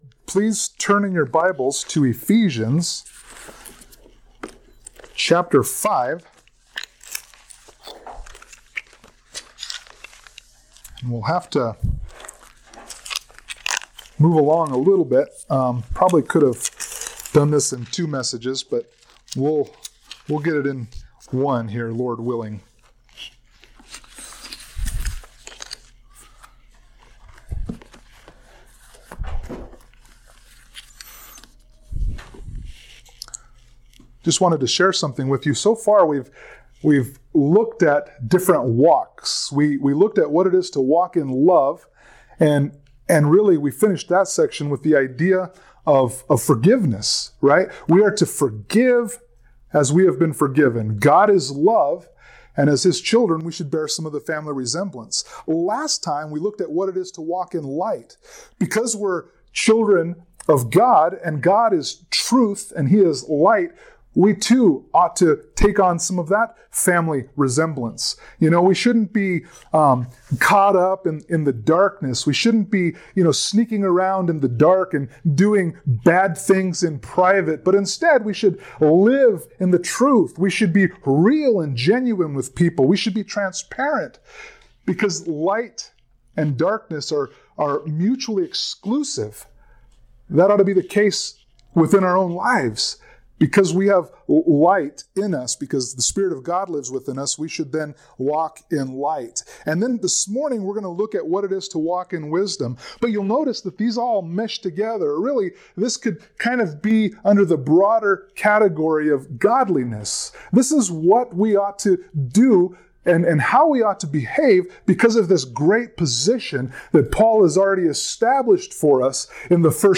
Passage: Ephesians 5:15-20 Service Type: Sunday Morning Worship